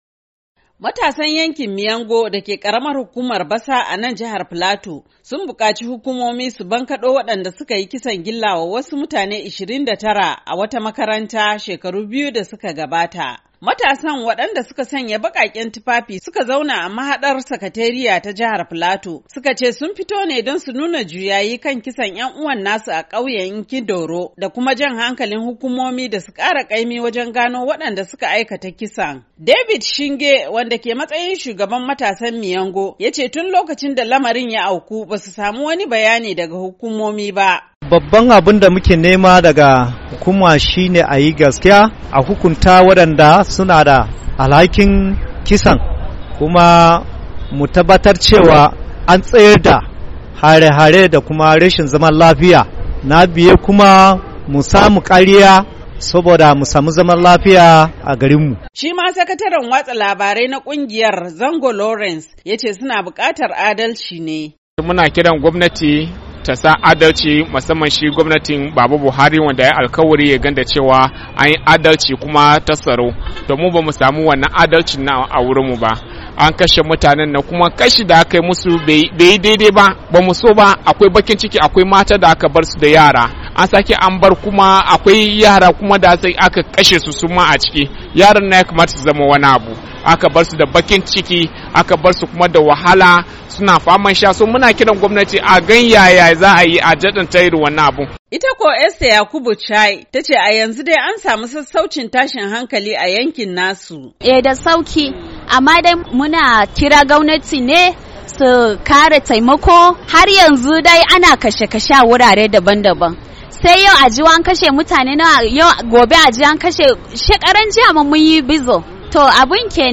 Kwamishinan yada labaran jihar Filato, Dan Manjang yace gwamnati na iyakar kokarinta wajen samun zaman lafiya a jihar.